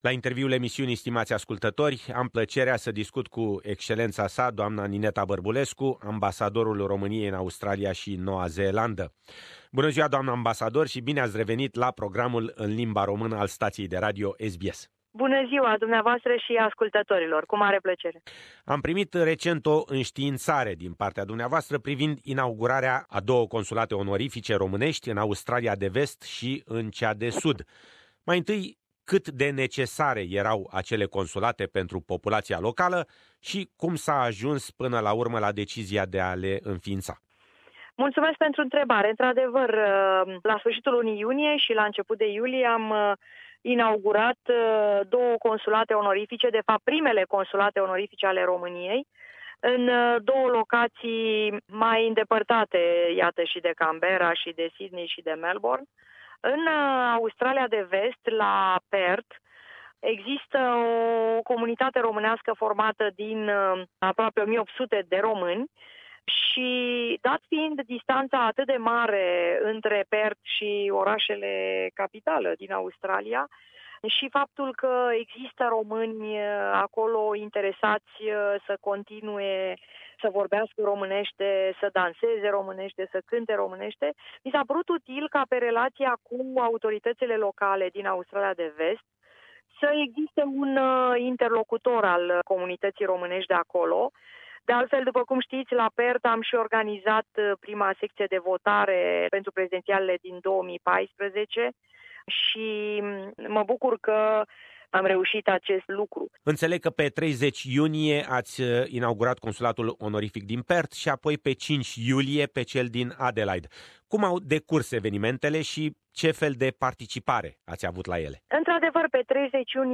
Noi Consulate onorifice romanesti in Perth si Adelaide - Interviu cu Ambasadorul Romaniei in Australia si Noua Zeelanda, Nineta Barbulescu